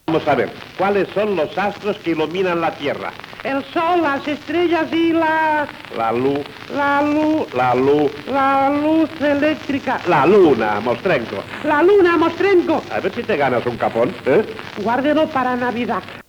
Entreteniment
Presentador/a